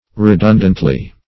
redundantly - definition of redundantly - synonyms, pronunciation, spelling from Free Dictionary Search Result for " redundantly" : The Collaborative International Dictionary of English v.0.48: Redundantly \Re*dun"dant*ly\ (r?*d?n"dant*l?), adv. In a refundant manner.